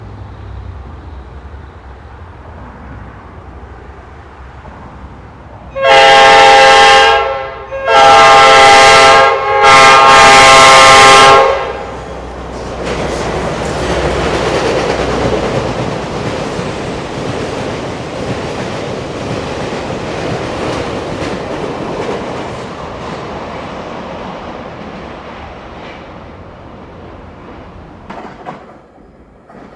🔊 sped over it.